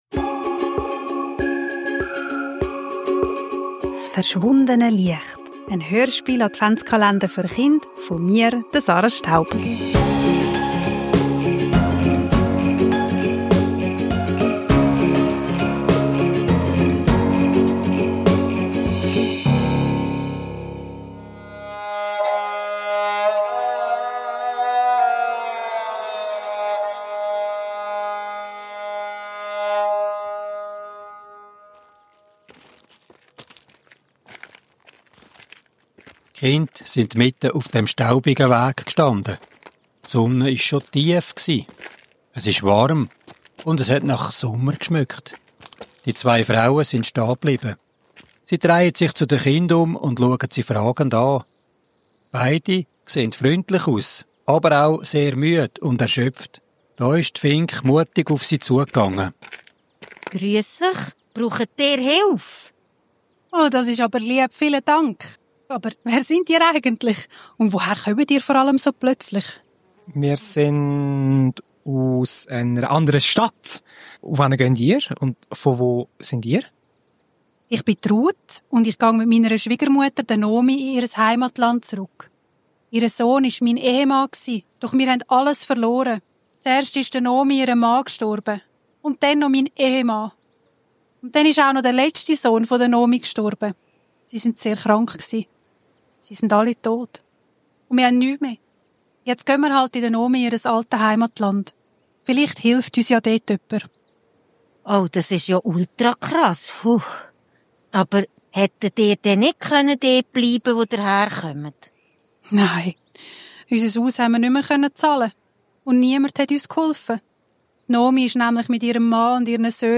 Kinder, Advent, Hörspiel, Weihnachten, Kindergeschichte